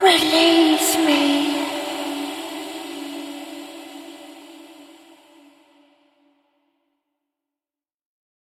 Женский прирак разговаривает
zhenskij_prirak_razgovarivaet_1rg.mp3